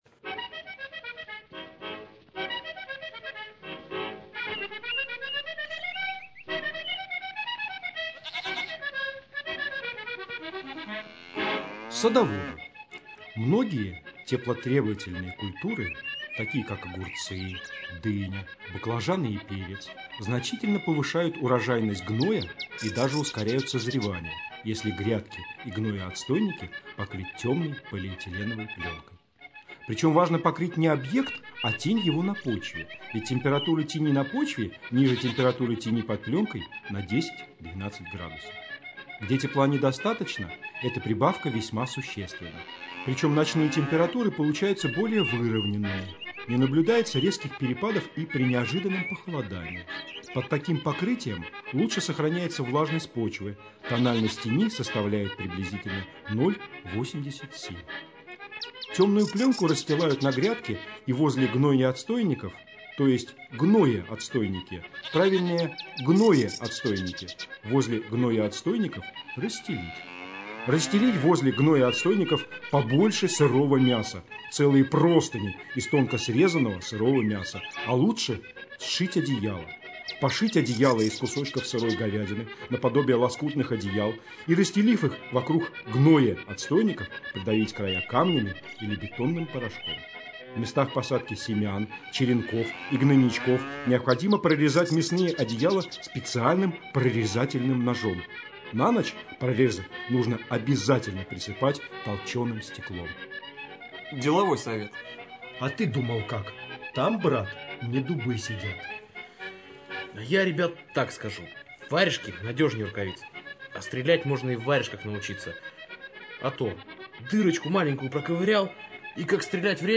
Радиопостановка пьесы Владимира Сорокина «Землянка», сделанная во время гастролей любительской театральной труппы журнала Game.EXE в кемеровском ДК «Металлург» в 2003 году.